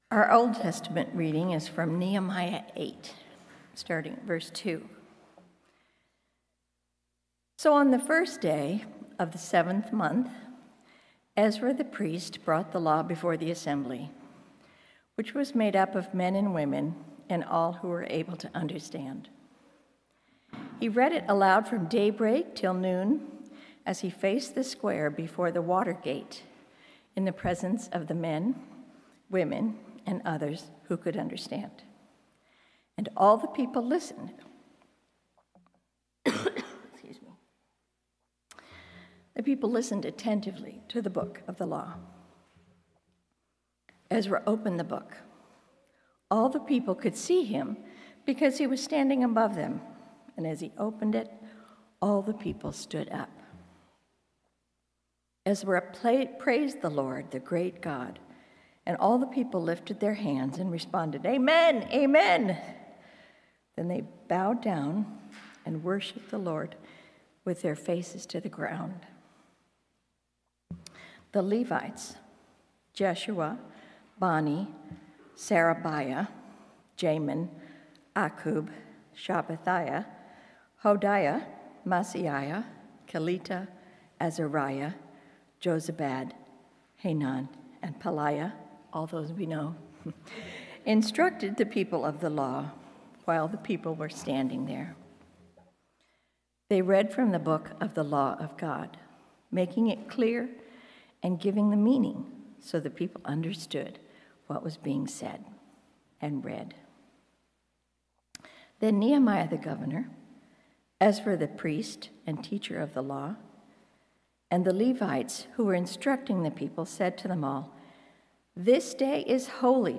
Sermons | Washington Community Fellowship